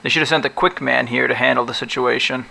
Human Male, Age 29